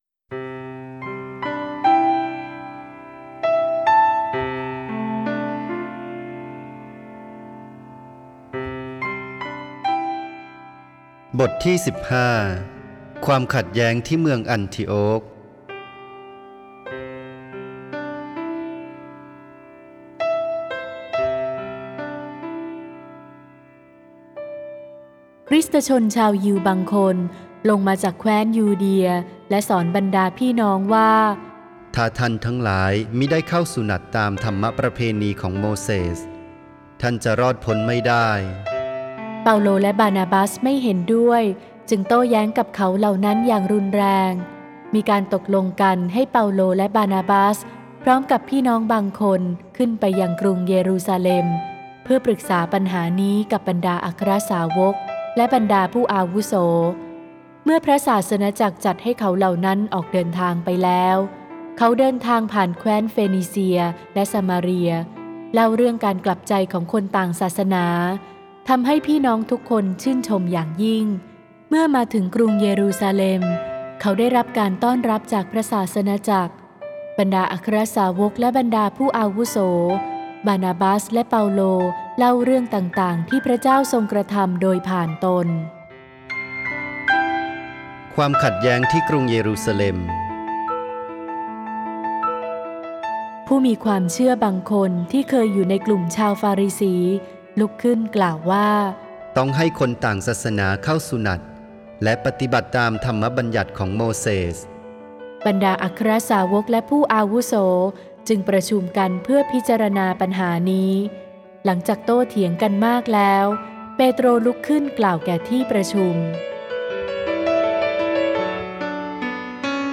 (ไฟล์ "เสียงวรสาร" โดย วัดแม่พระกุหลาบทิพย์ กรุงเทพฯ)